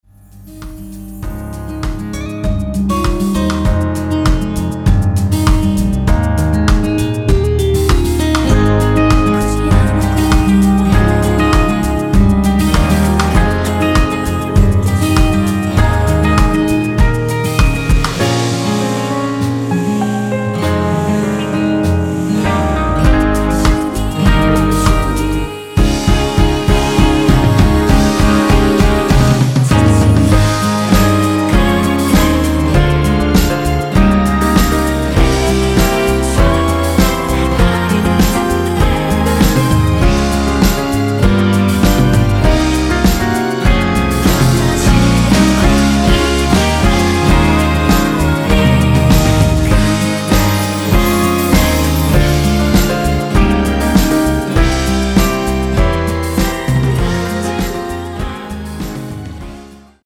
원키 코러스 포함된 MR입니다.(미리듣기 확인)
Ab
앞부분30초, 뒷부분30초씩 편집해서 올려 드리고 있습니다.